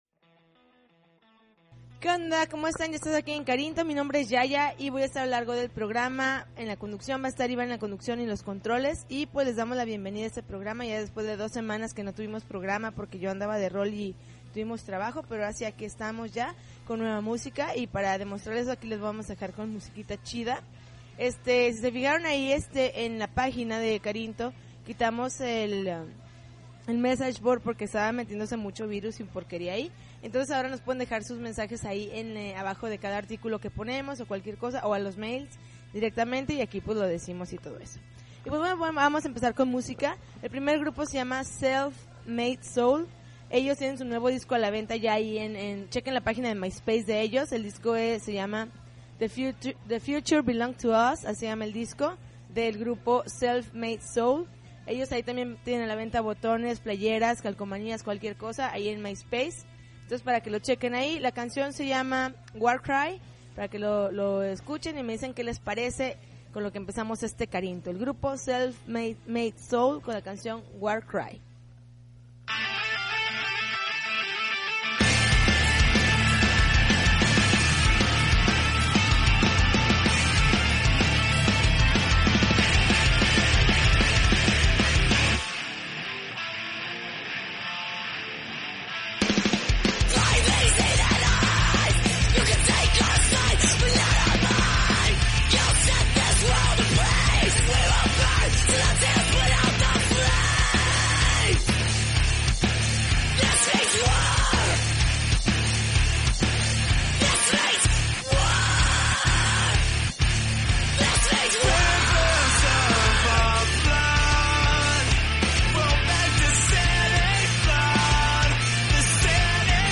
March 1, 2009Podcast, Punk Rock Alternativo